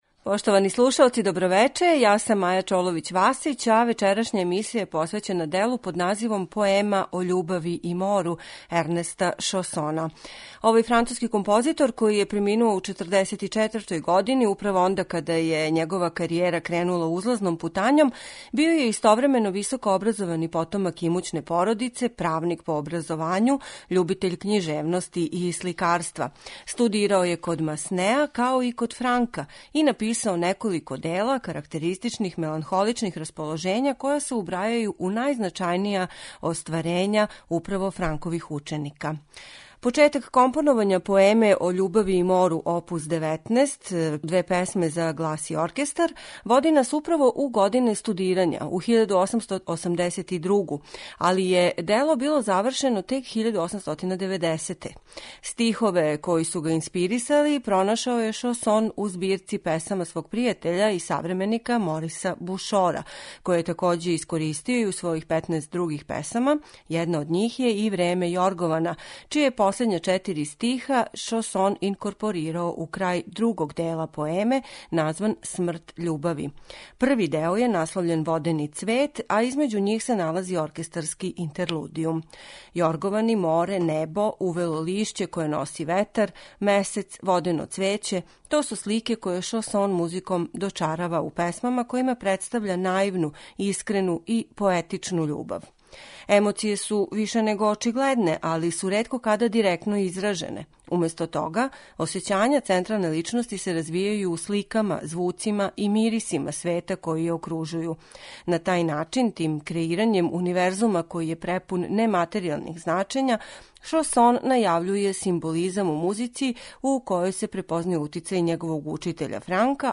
Ернест Шосон - Поема о љубави и мору оп. 19, за глас и оркестар
Дело чине две песме - Водени цвет и Смрт љубави, које повезује оркестарски интерлудијум. Типично за овог француског композитора, у песмама преовлађује меланхолично расположење, којим је суптилно представљена наивна, искрена и поетична љубав. Поему ћете слушати у извођењу мецосопрана Сузан Грејем и симфонијског оркестра којим диригује Жан Паскал Тортелије.